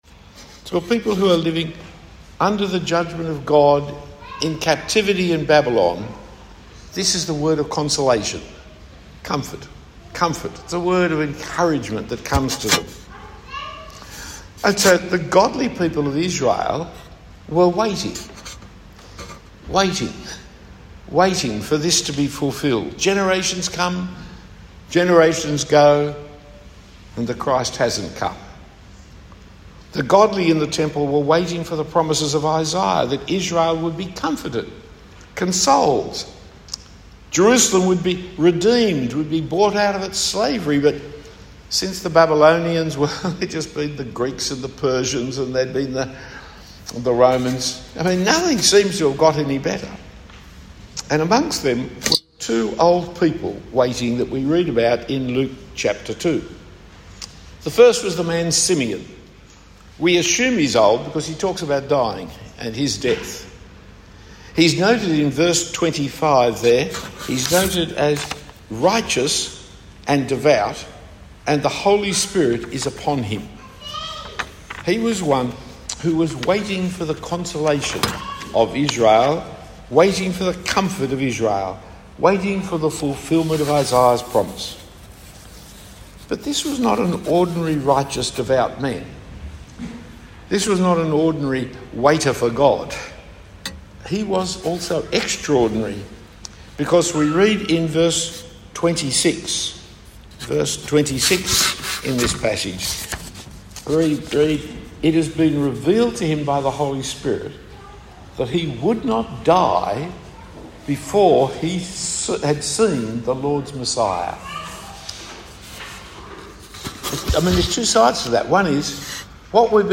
Talk 3 of 4 given in the Christmas period at St Nicolas Coogee.
The talk starts in the outline at 2 Waiting in the Temple as the beginning of the talk is missing.